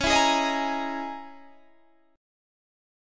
Listen to Dbm6add9 strummed